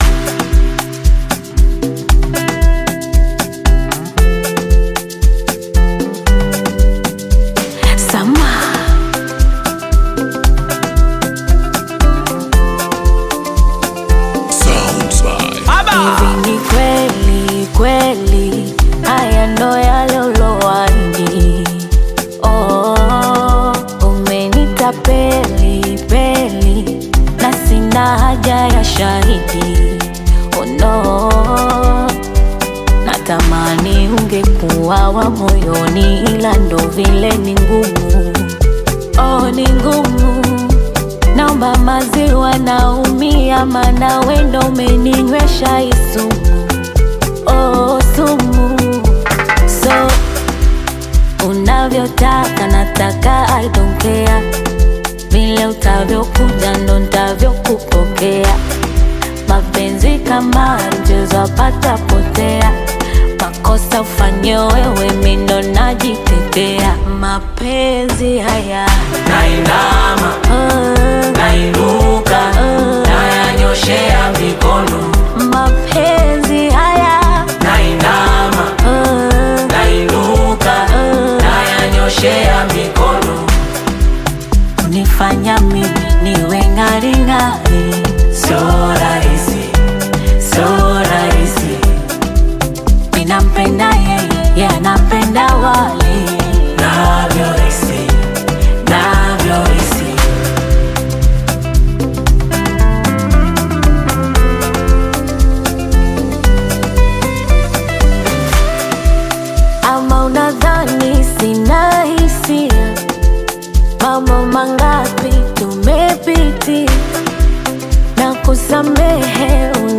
Tanzanian Afro pop
a bold anthem of self confidence and emotional freedom